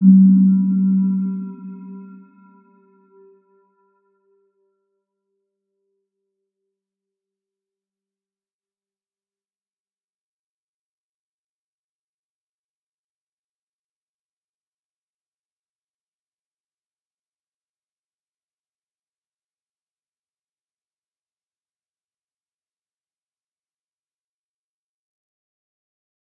Round-Bell-G3-mf.wav